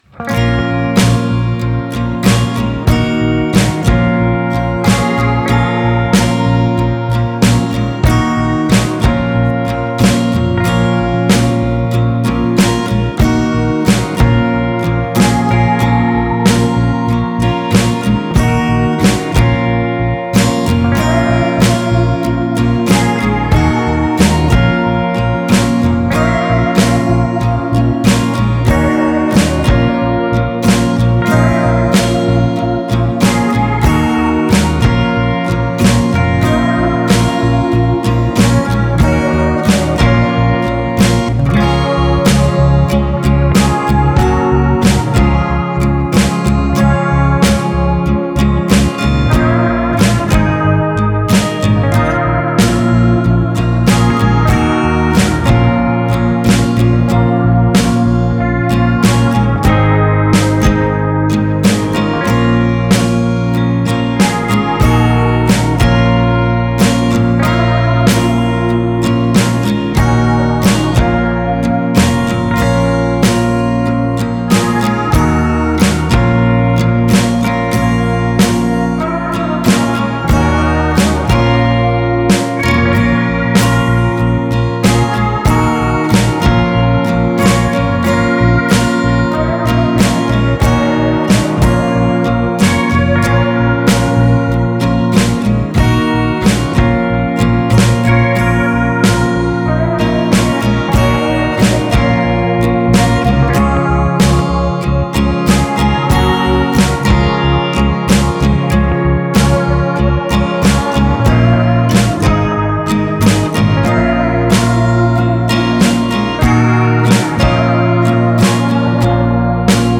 This music is in studio youtube